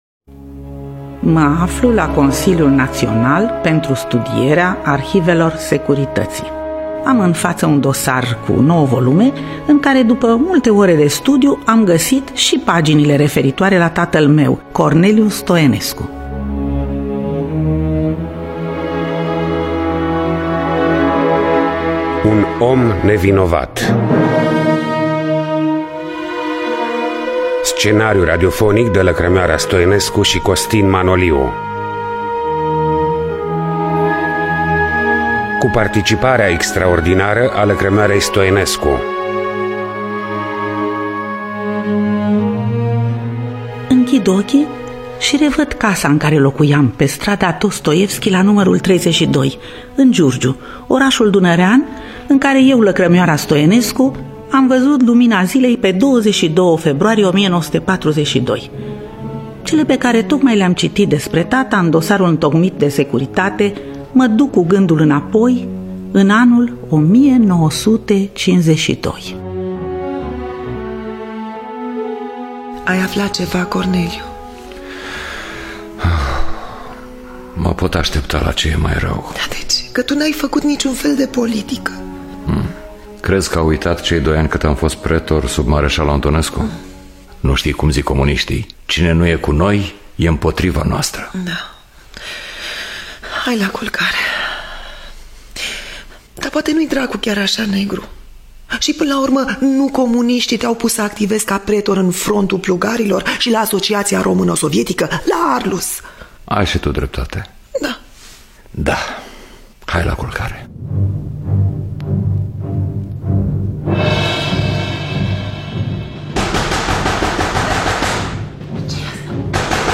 Biografii, memorii: Un om nevinovat. Scenariu radiofonic